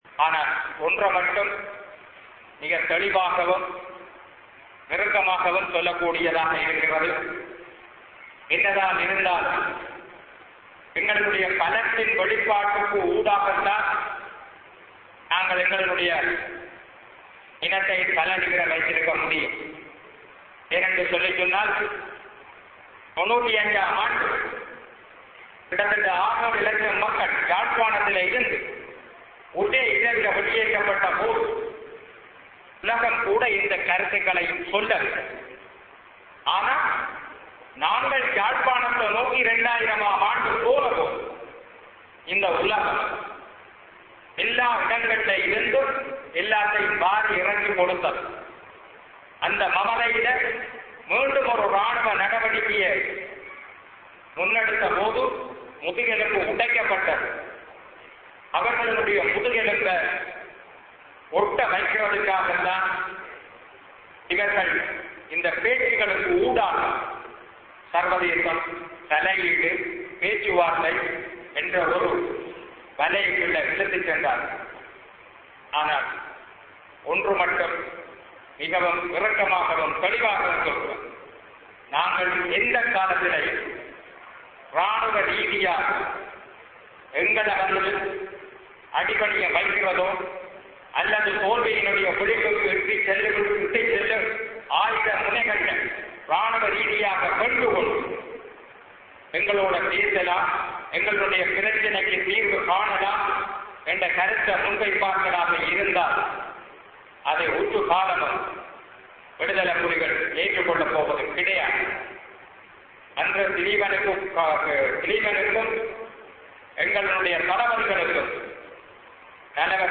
Voice: Extracts from Col. Soosai's address
The event took place at Puthukkudiyiruppu Golden Jubilee Hall from 6:30 p.m. to 8:30 p.m.